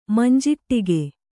♪ manjiṭṭige